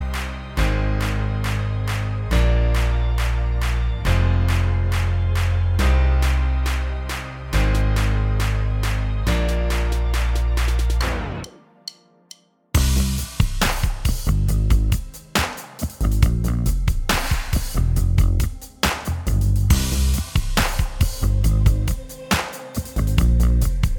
Minus All Guitars Pop (2010s) 3:08 Buy £1.50